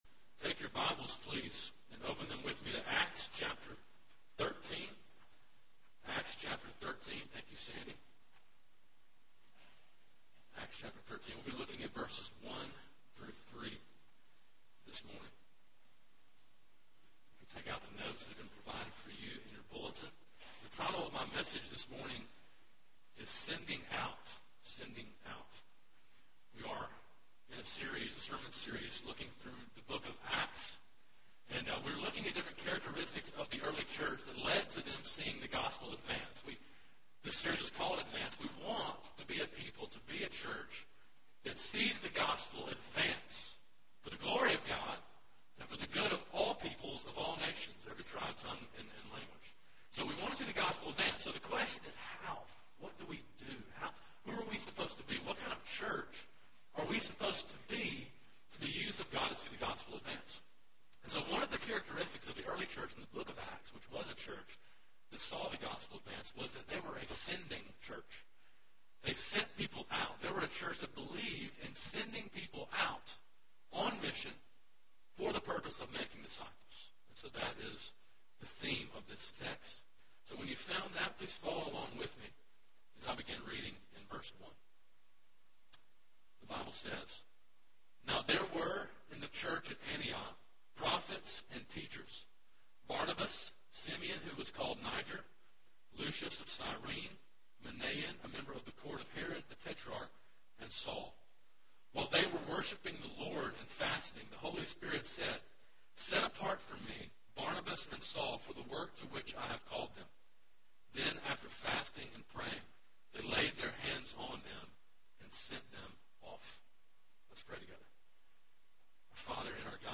september-4-2011-morning-sermon.mp3